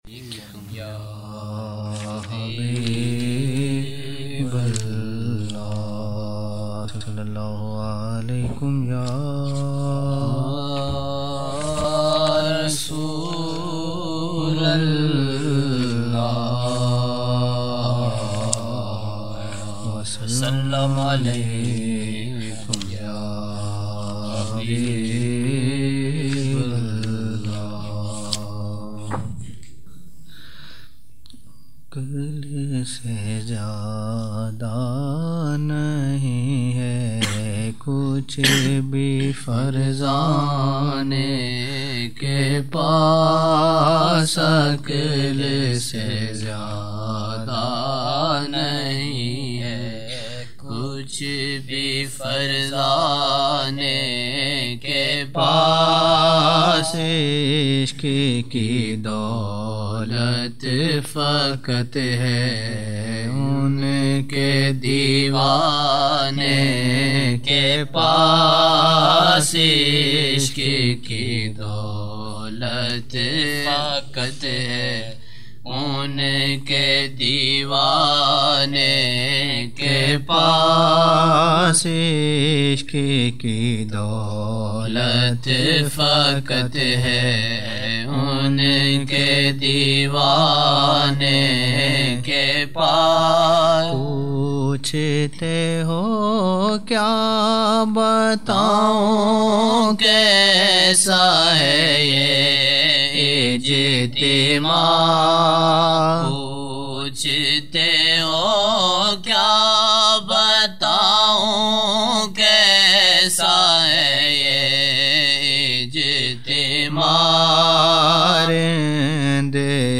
13 November 1999 - Isha mehfil (5 Shaban 1420)
Naat Shareef